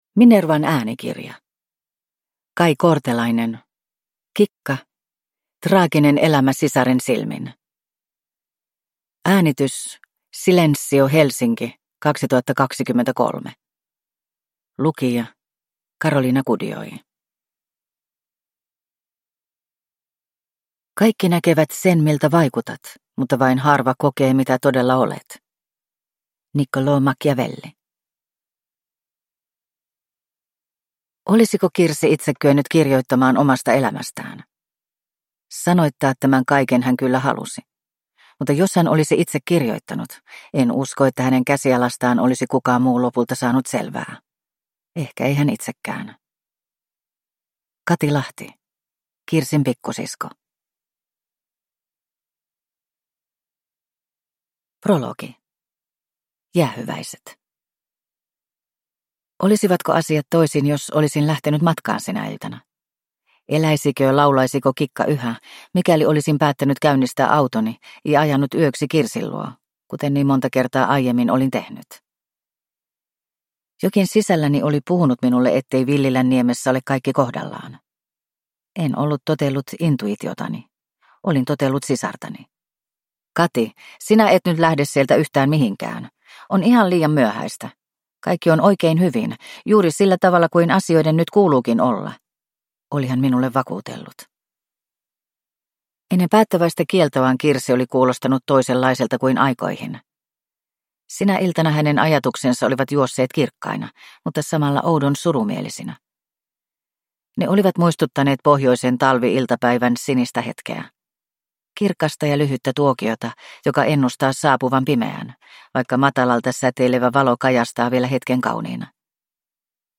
Kikka – Ljudbok – Laddas ner